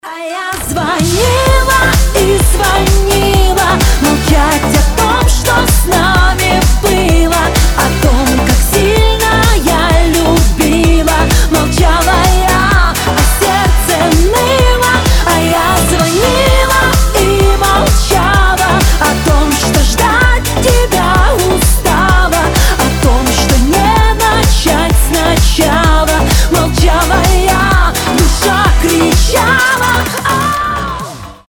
поп
громкие